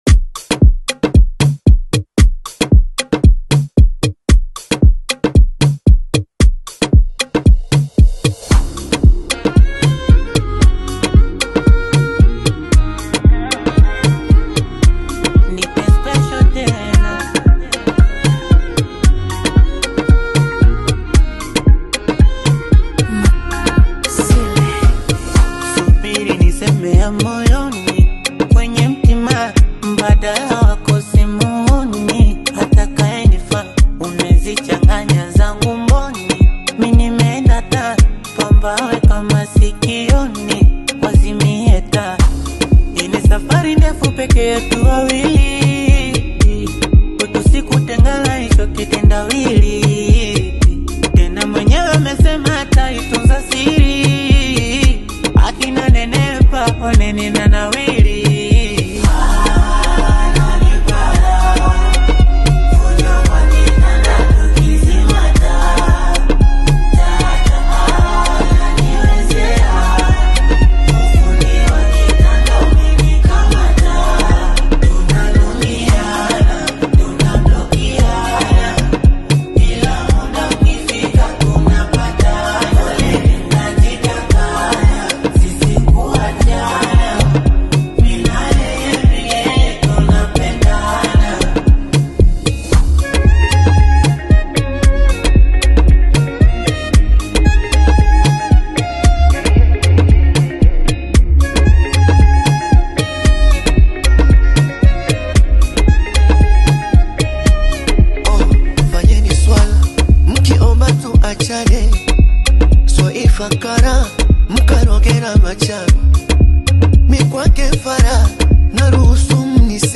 Bongo Flava music track
Bongo Flava